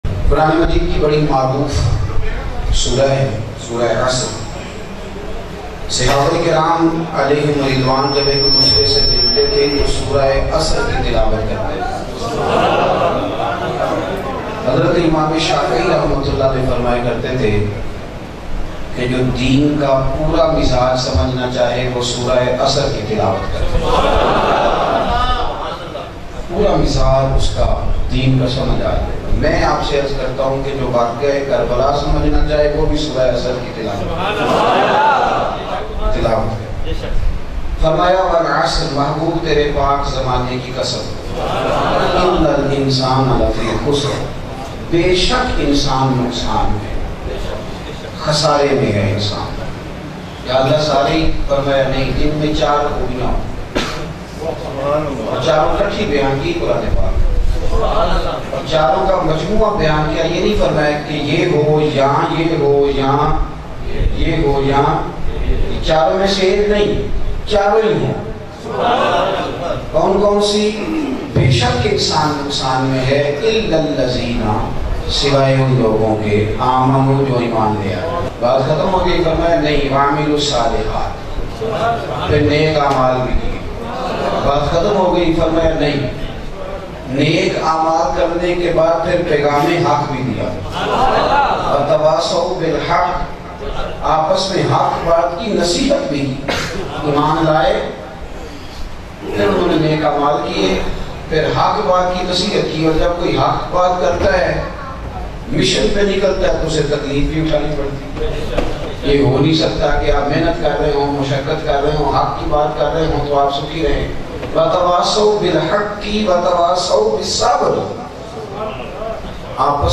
Imam Shafi Ka Qoul Bayan MP3 Download